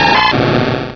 pokeemerald / sound / direct_sound_samples / cries / croconaw.aif